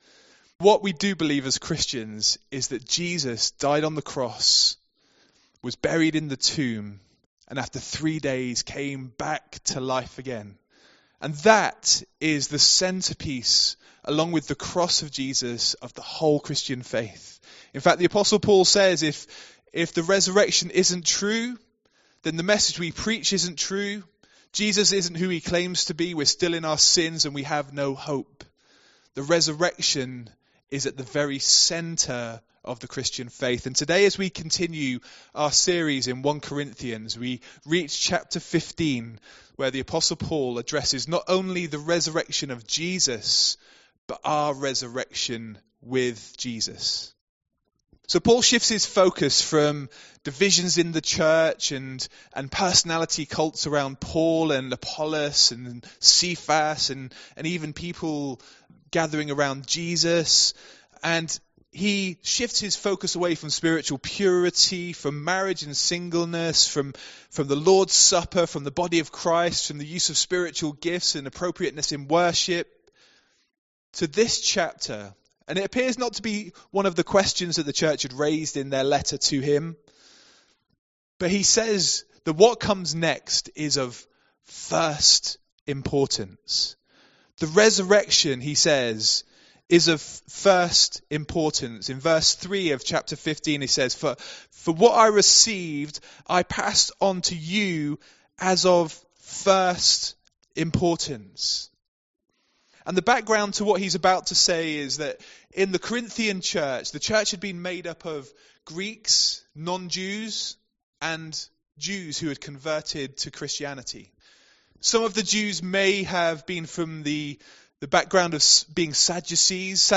MP3 SUBSCRIBE on iTunes(Podcast) Notes Sermons in this Series The series looking at 1 Corinthians continues and today we look at Chapter 15: 35-58.